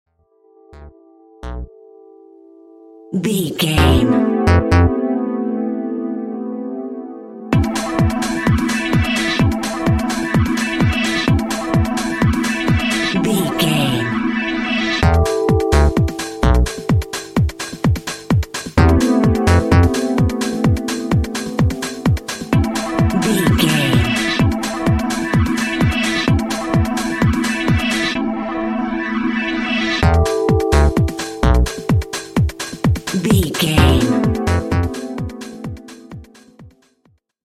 Clubbing Underground.
Aeolian/Minor
energetic
dark
futuristic
hypnotic
industrial
drum machine
synthesiser
Drum and bass
break beat
electronic
sub bass
techno
synth lead
synth bass